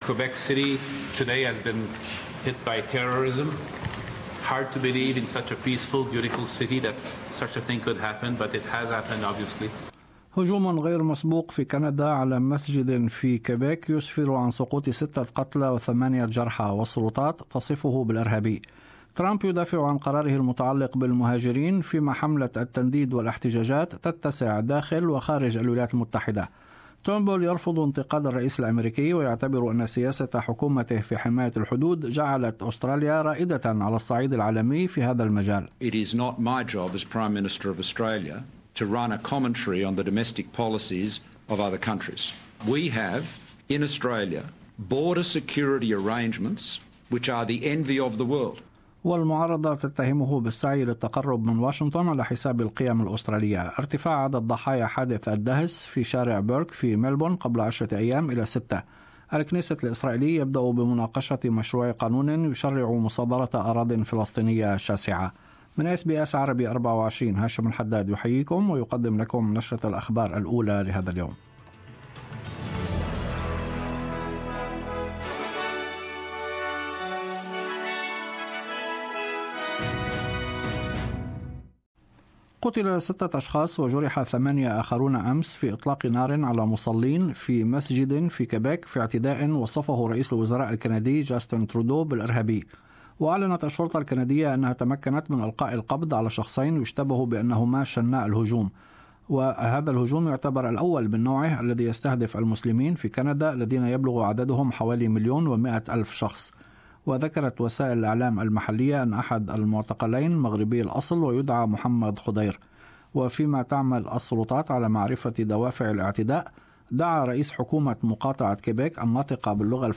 Morning Bulletin News